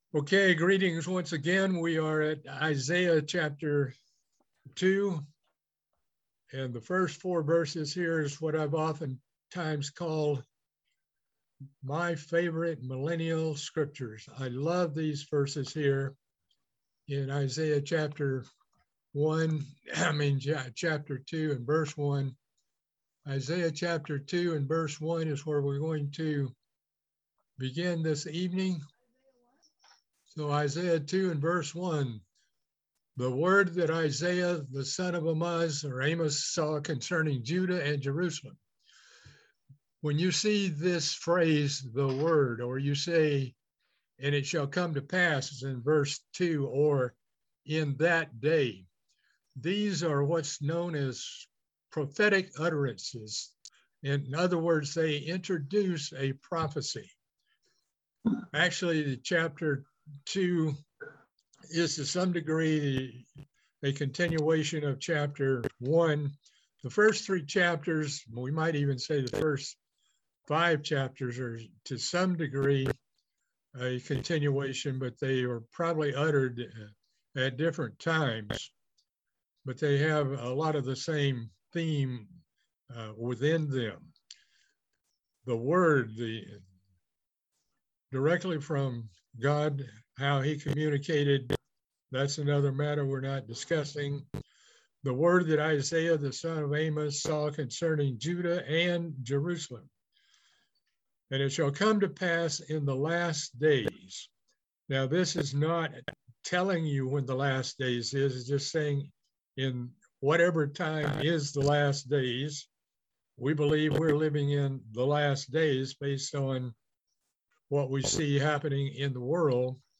A continuing Bible Study series on the book of Isaiah.